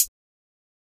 HiHat (24).wav